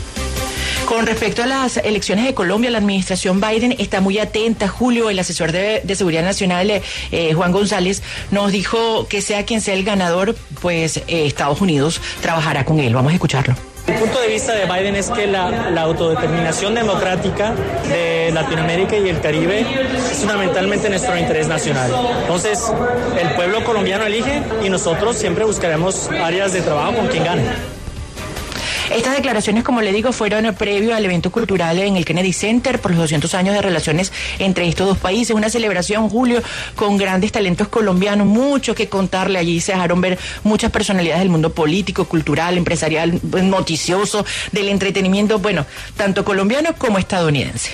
Juan González, director de Asuntos Hemisféricos del Consejo de Seguridad Nacional de la Casa Blanca, habló en La W sobre las elecciones en Colombia del próximo 19 de junio.